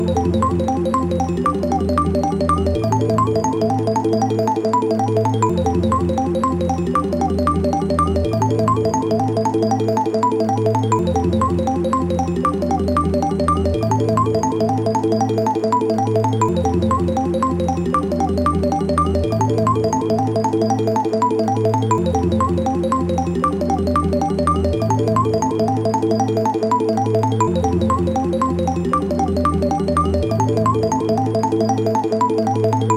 TRIP-HOP